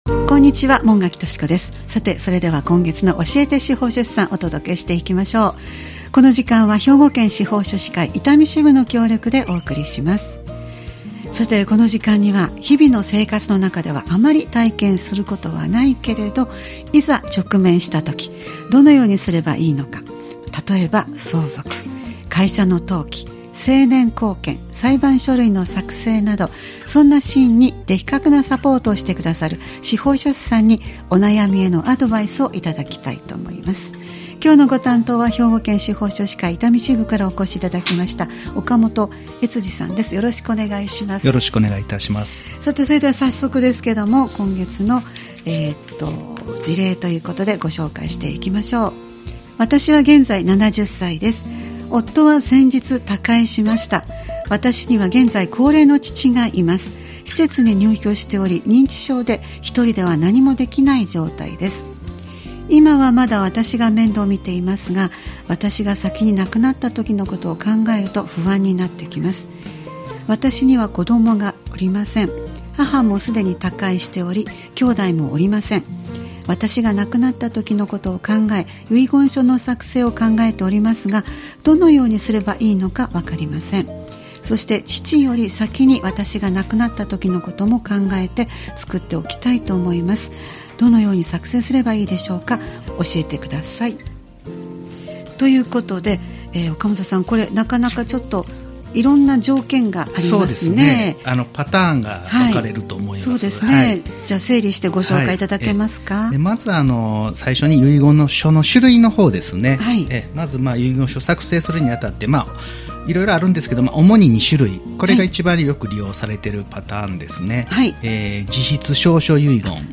毎月第2水曜日は「教えて司法書士さん」を配信しています。毎回スタジオに司法書士の方をお迎えして、相続・登記・成年後見・裁判書類の作成などのアドバイスを頂いています。